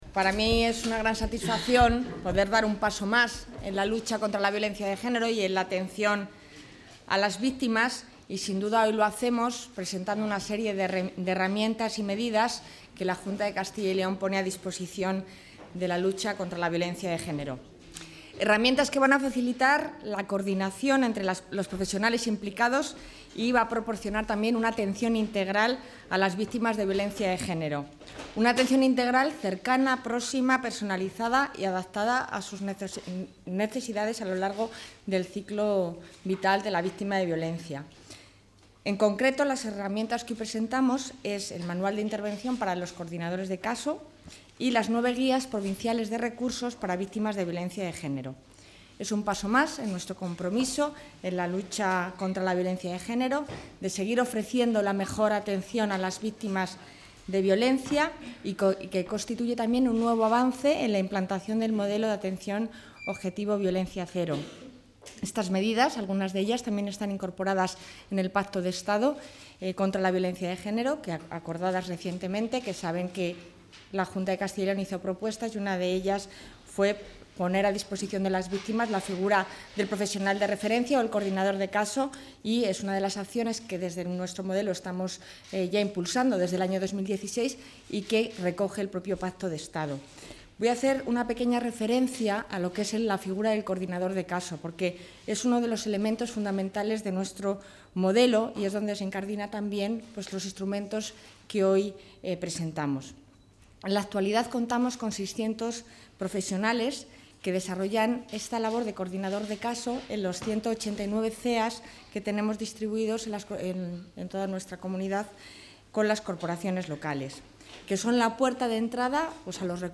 Consejera de Familia e Igualdad de Oportunidades.
Consejera de Familia e Igualdad de Oportunidades Atención a los medios Presentación de la consejera del Manual de intervención para la...